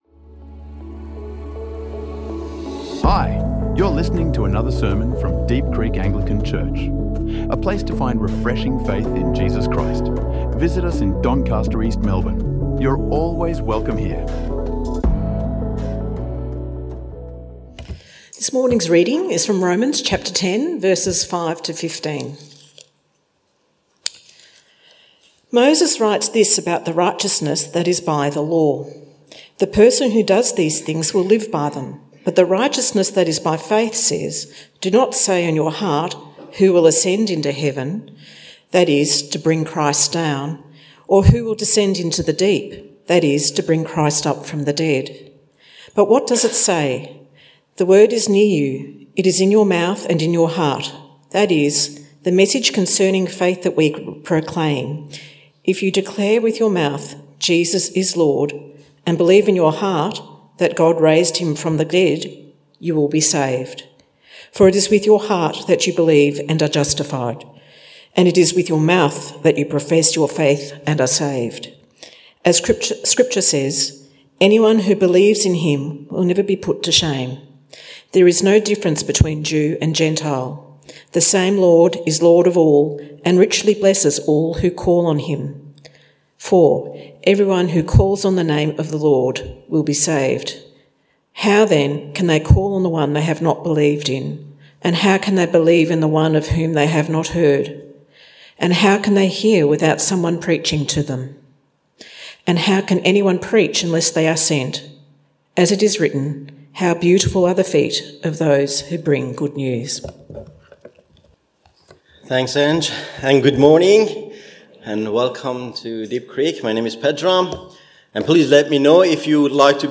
| Sermons | Deep Creek Anglican Church